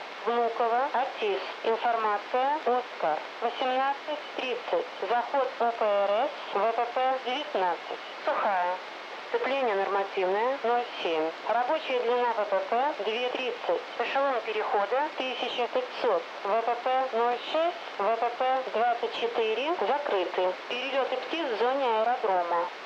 Внуково-АТИС